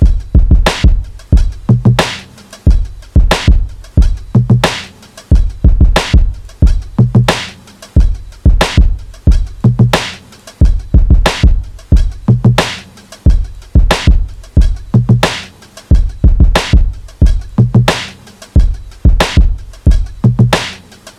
Unknown Drums.wav